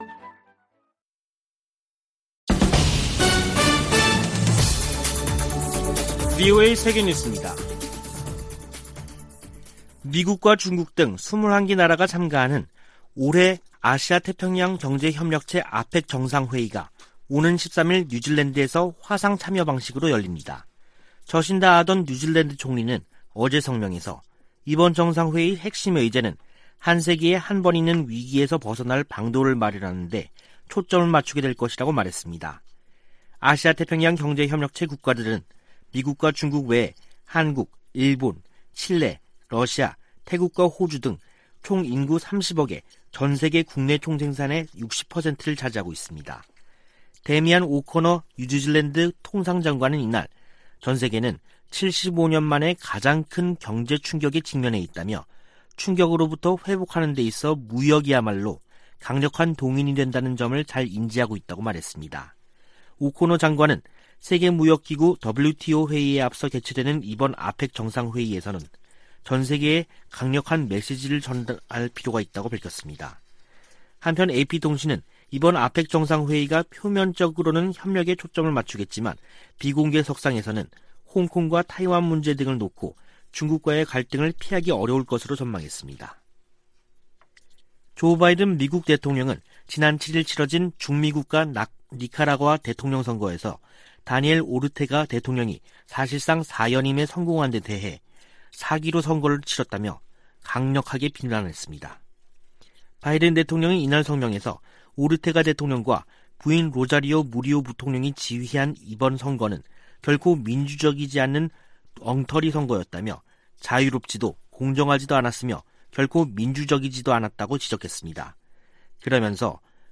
VOA 한국어 간판 뉴스 프로그램 '뉴스 투데이', 2021년 11월 9일 3부 방송입니다. 미국 민주당 상·하원 의원들이 대북 인도적 지원을 위한 규정 완화를 촉구하는 서한을 조 바이든 대통령에게 보냈습니다. 일본은 북 핵 위협 제거되지 않은 상태에서의 종전선언을 우려하고 있을 것으로 미국의 전문가들이 보고 있습니다. 최근 북-중 교역이 크게 증가한 가운데 코로나 사태 이후 처음으로 열차가 통행한 것으로 알려졌습니다.